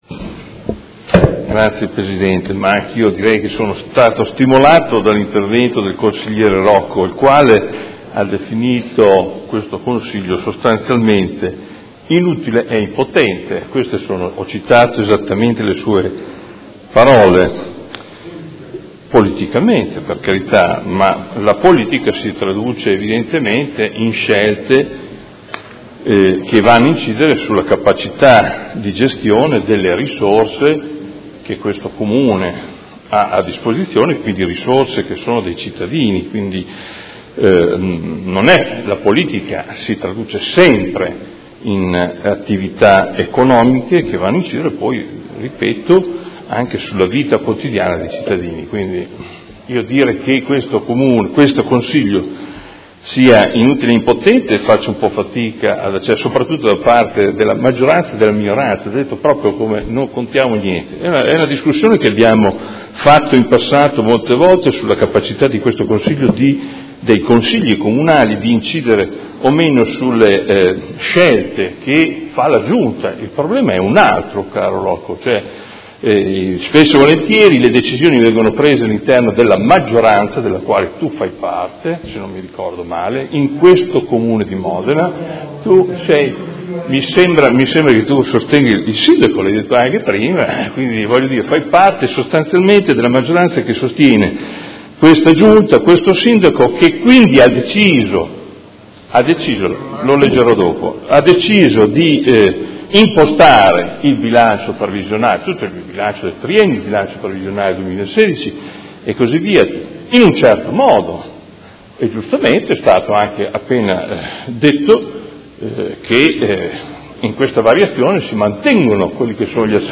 Seduta del 22/09/2016 Proposta di deliberazione: Bilancio 2016-2018, Programma Triennale dei Lavori Pubblici 2016- 2018 – Variazione di Bilancio n. 4. Dibattito